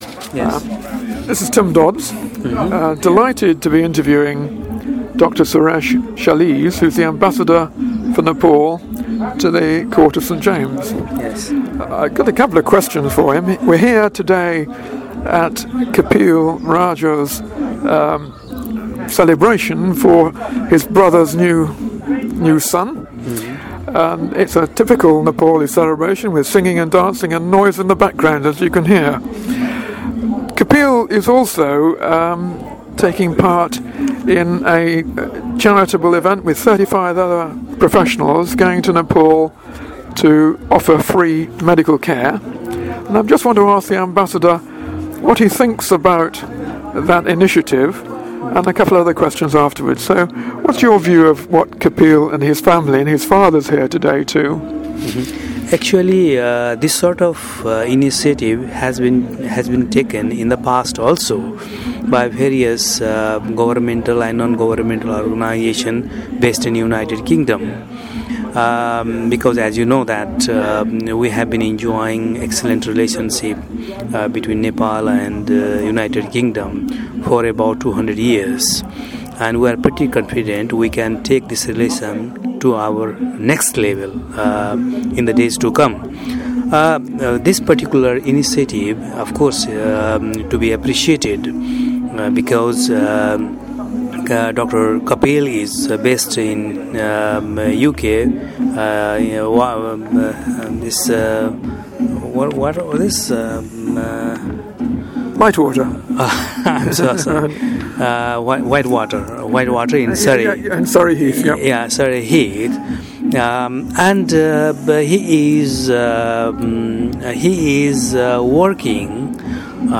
Cllr Tim Dodds interviews Nepalase Ambassador Dr Suresh Chalise